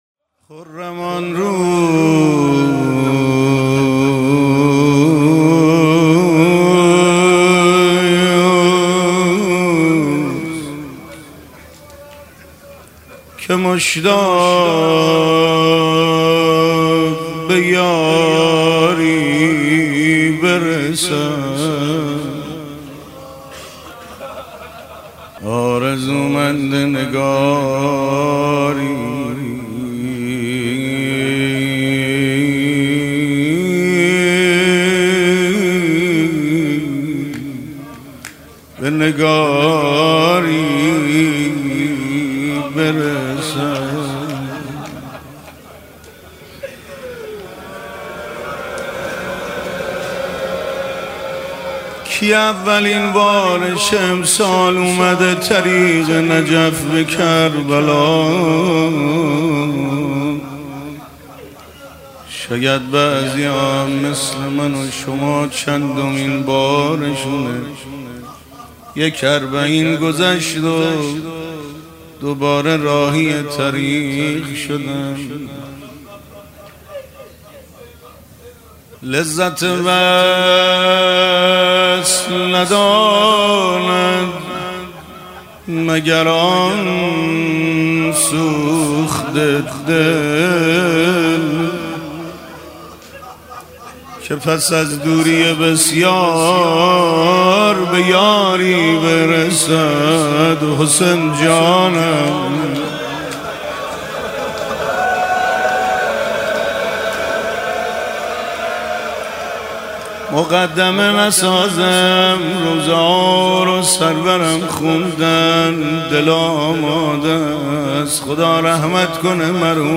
سخنرانی: سخنرانی شب سوم اربعین آیت الله میرباقری